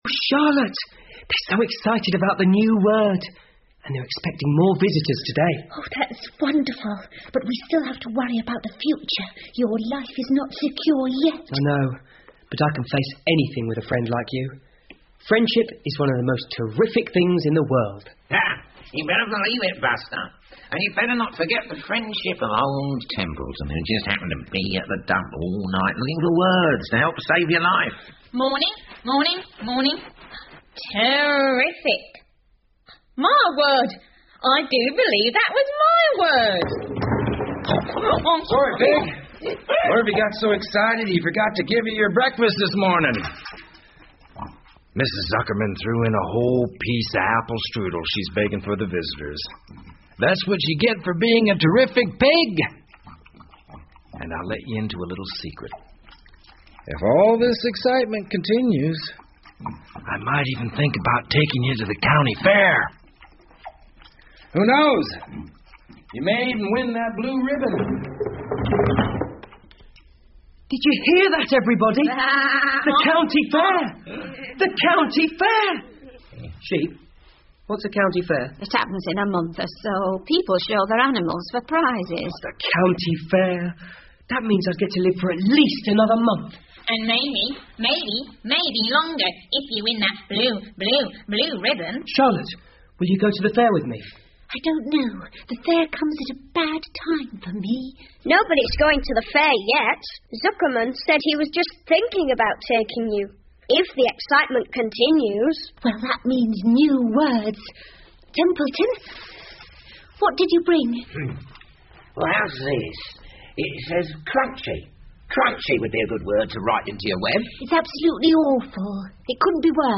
夏洛的网 Charlottes Web 儿童广播剧 8 听力文件下载—在线英语听力室